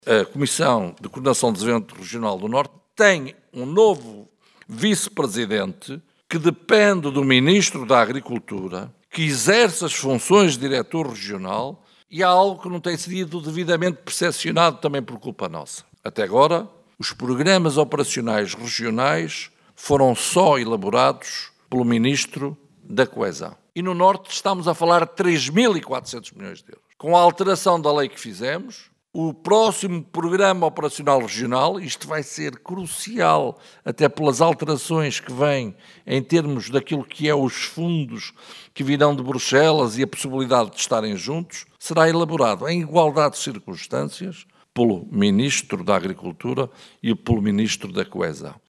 José Manuel Fernandes, esteve em Mirandela, na apresentação da candidatura da AD à Câmara Municipal.